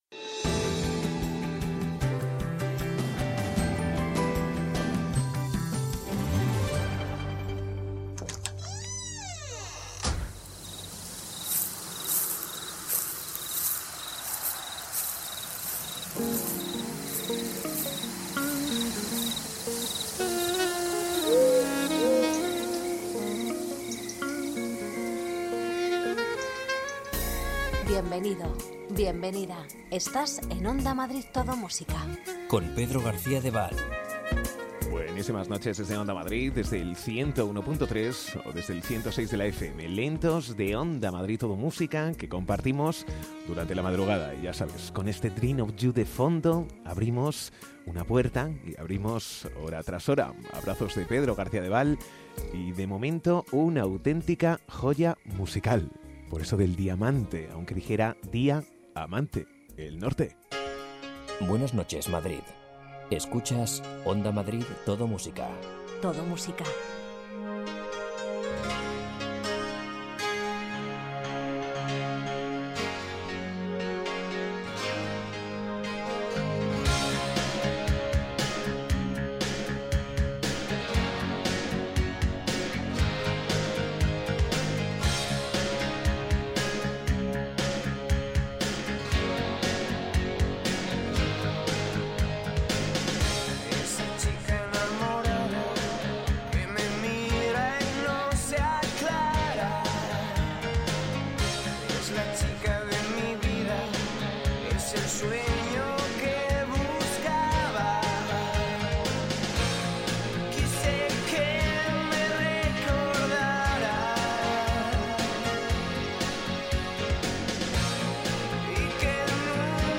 Ritmo tranquilo, sosegado, sin prisas...